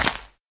grass3.ogg